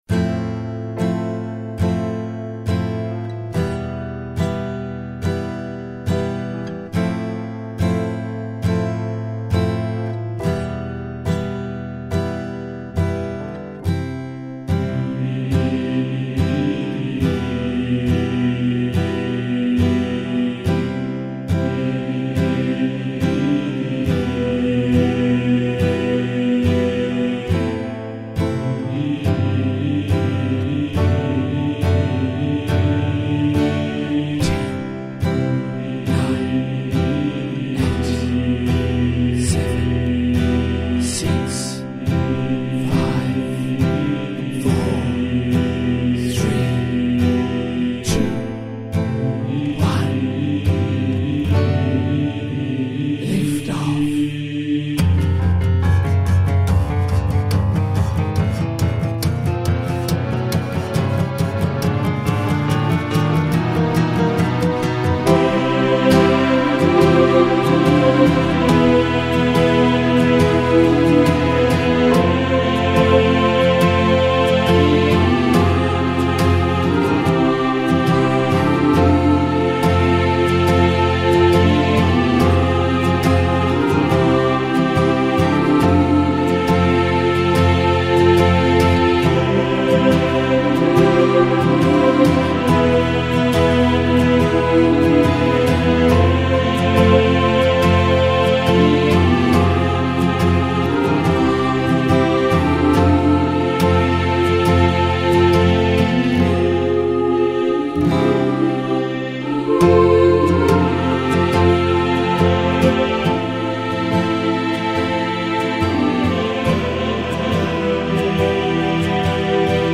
Mixed Choir Version
rock, soft rock, popular music of the 20th century
synth choir audio sample